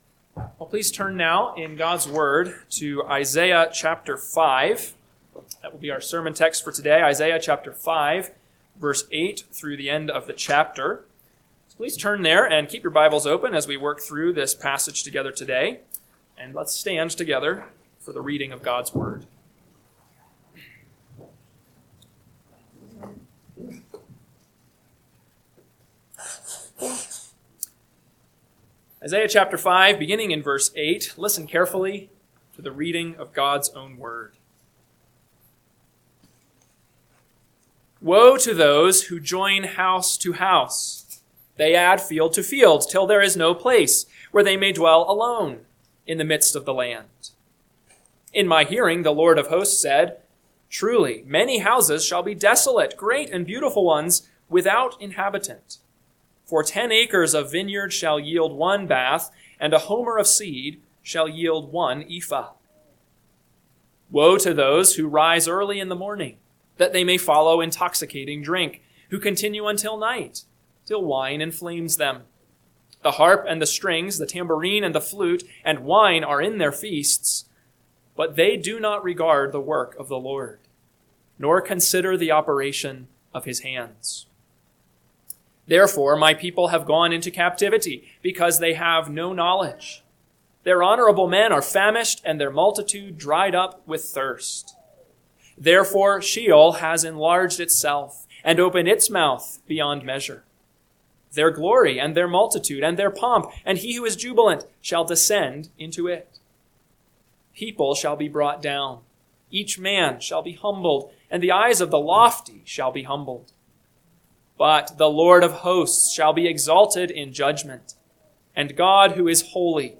AM Sermon – 11/23/2025 – Isaiah 5:8-30 – Northwoods Sermons